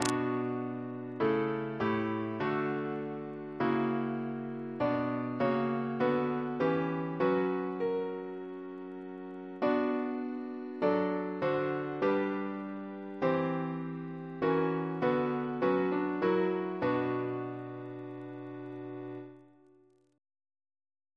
Double chant in B minor Composer